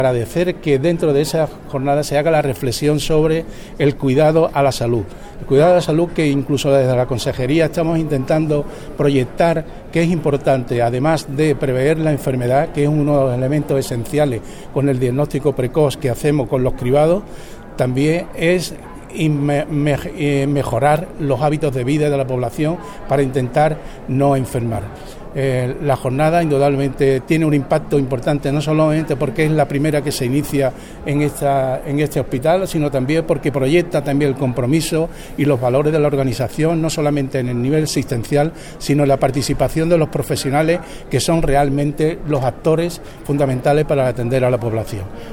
Celebrada la Jornada «El abordaje de la continuidad asistencial en CLM» en el Hospital Universitario de Cuenca: avances para un modelo asistencial centrado en las necesidades del paciente
Intervención de Joaquín Torres: